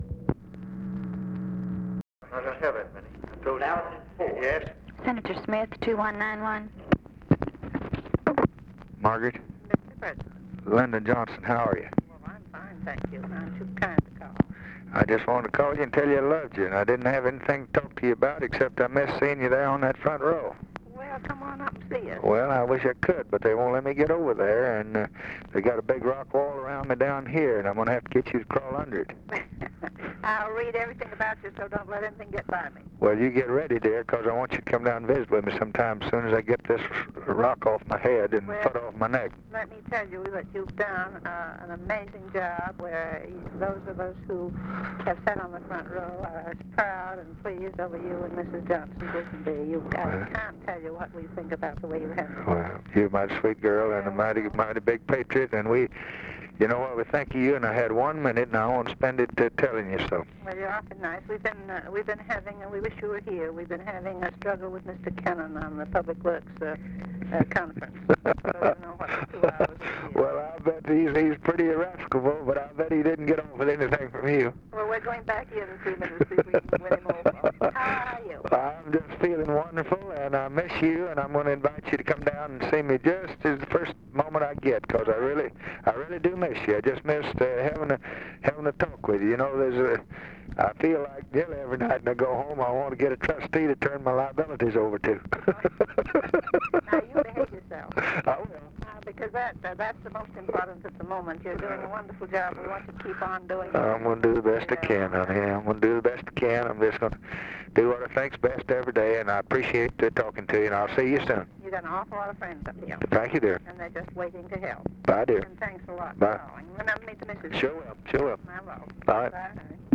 Conversation with MARGARET CHASE SMITH, December 10, 1963
Secret White House Tapes